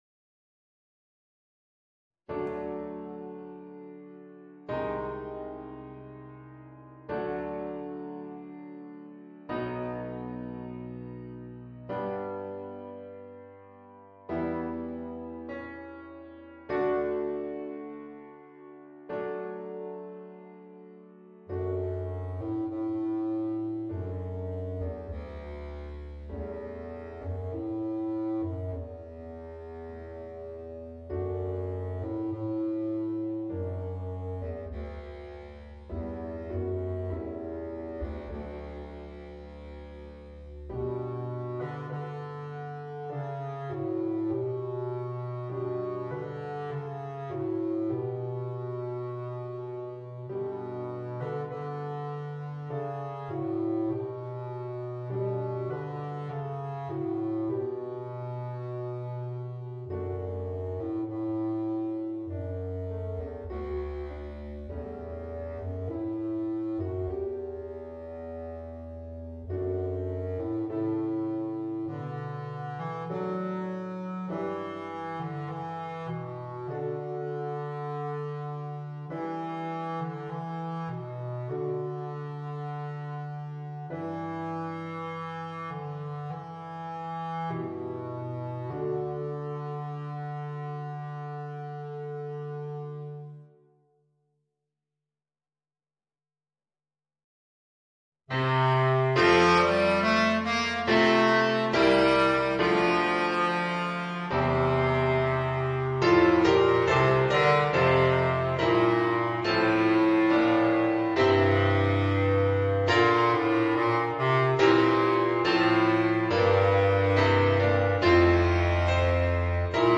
Bass Clarinet and Organ